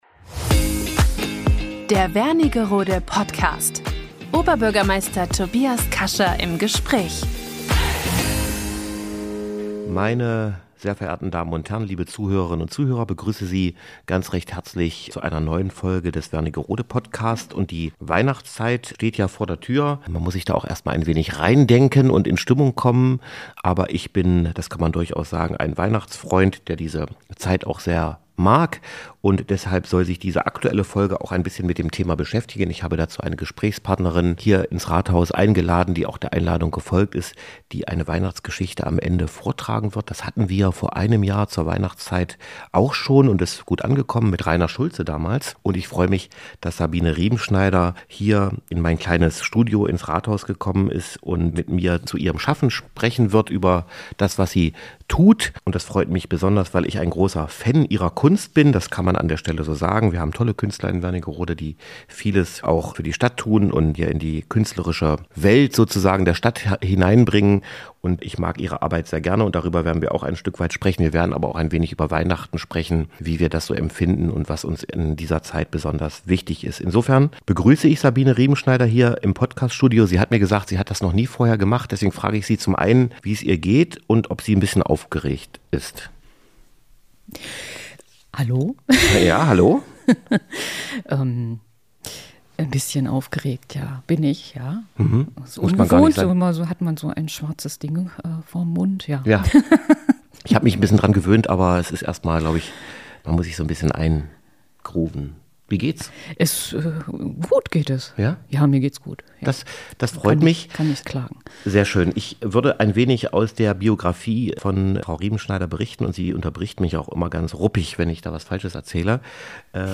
Wernigerode Podcast #39 - Oberbürgermeister Tobias Kascha im Gespräch